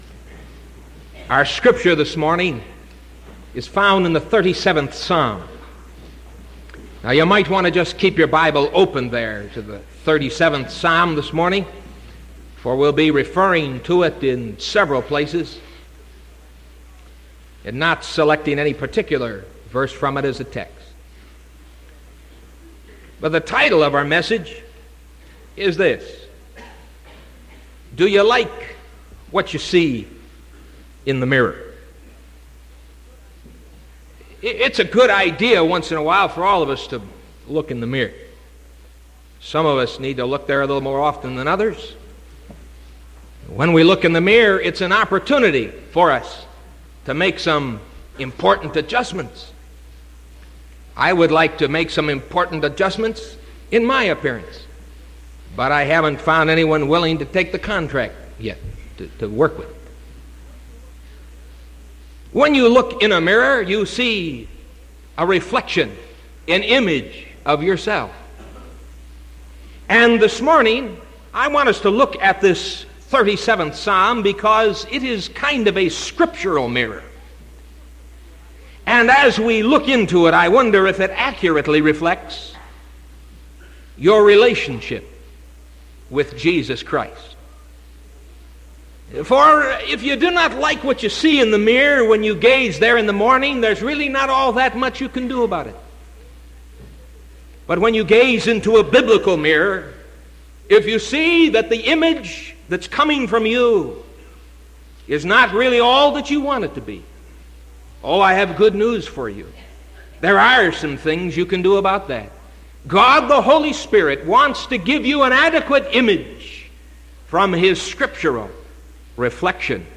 Sermon September 22nd 1974 AM